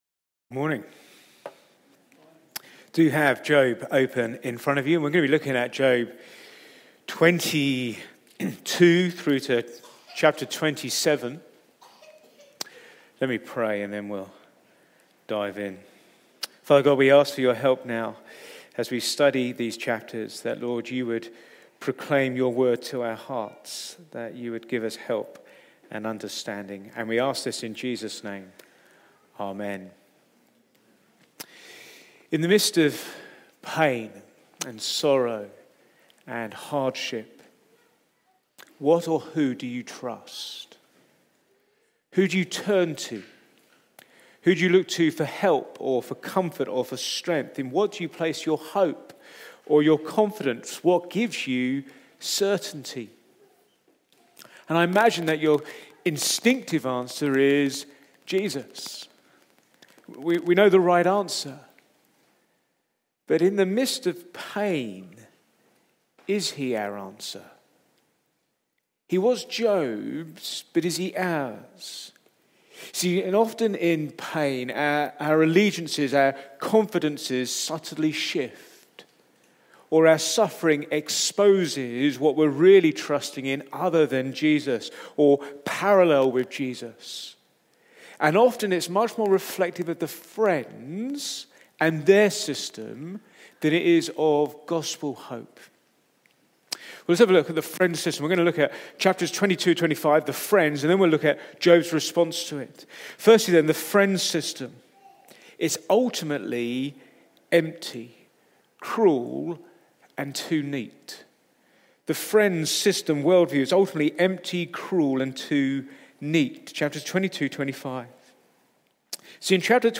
Back to Sermons God must be punishing you